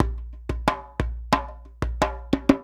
90 JEMBE1.wav